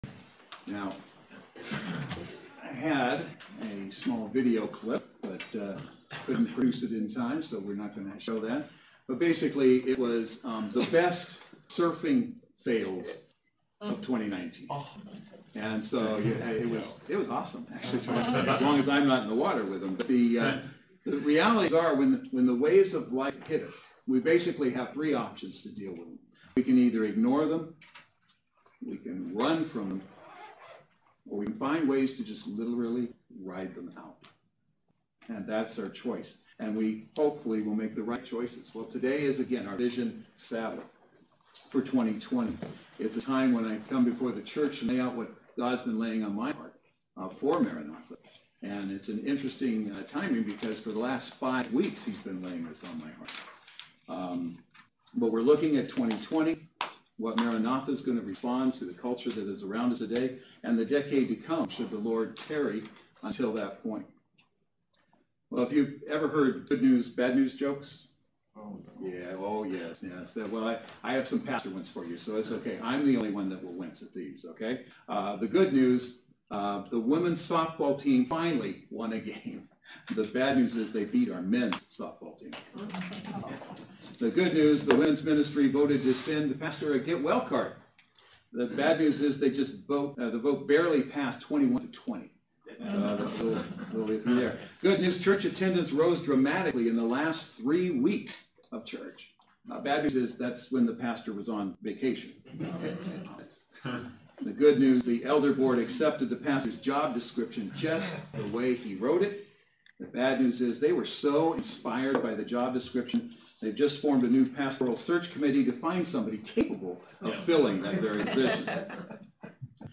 Misc Messages Service Type: Saturday Worship Service Speaker